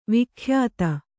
♪ vikhyāta